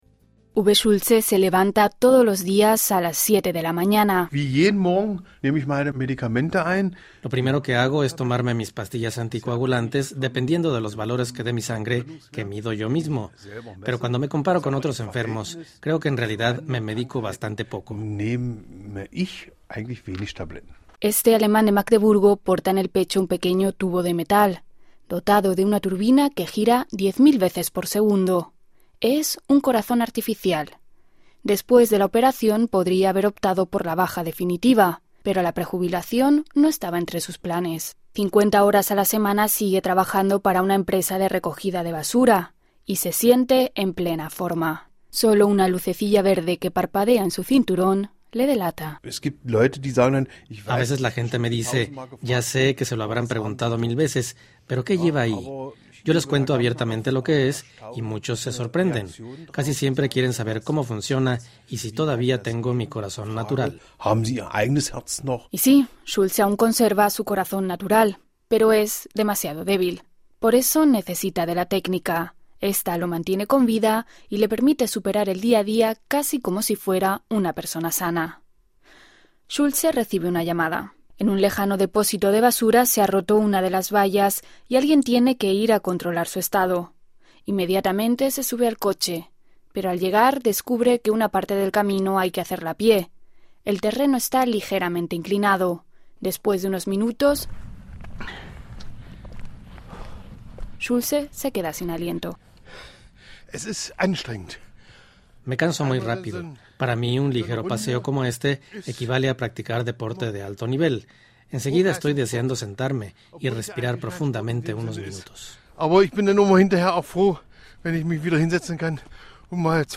La experiencia de un hombre alemán que ya ha tenido un transplante de corazón y podría llegar a someterse a otro. Escuche el informe de la Deutsche Welle.